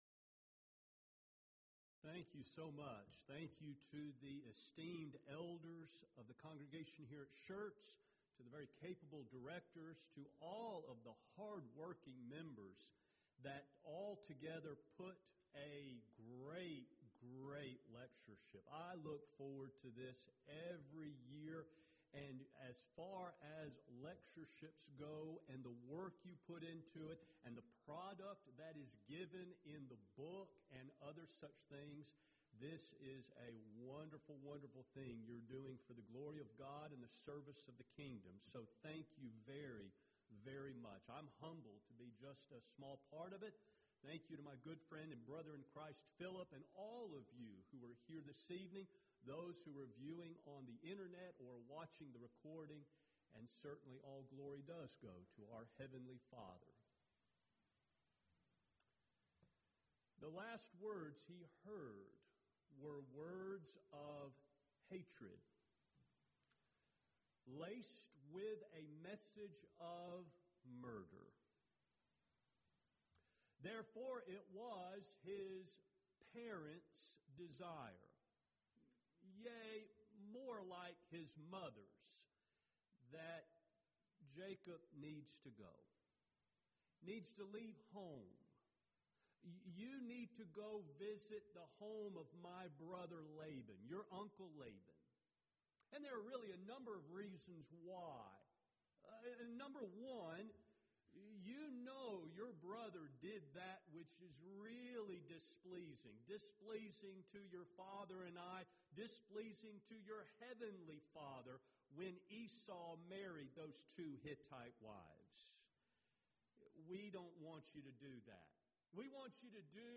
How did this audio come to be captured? Event: 16th Annual Schertz Lectures